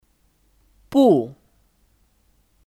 不 Bù / Bú (Kata keterangan): TidakContoh kalimat:他不来 Tā bù lái (Dia tidak datang)我不看 Wǒ bú kàn (Saya tidak lihat)
不 (Bù 不)